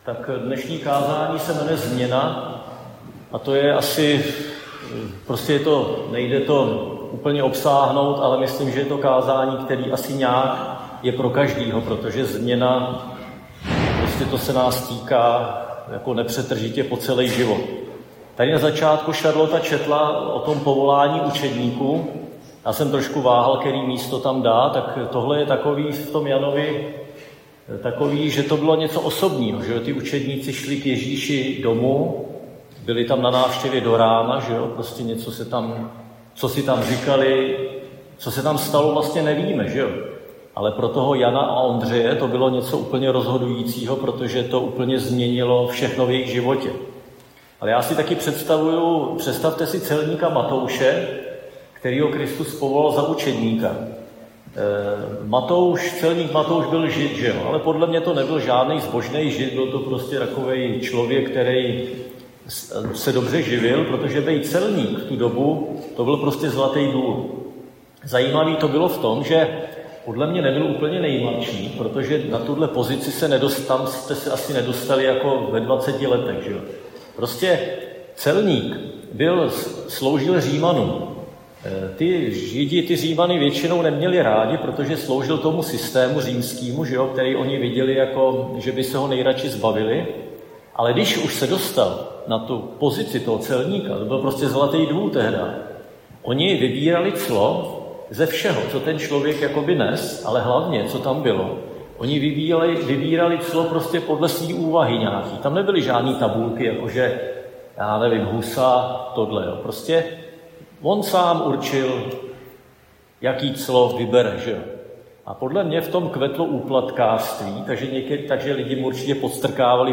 Křesťanské společenství Jičín - Kázání 26.10.2025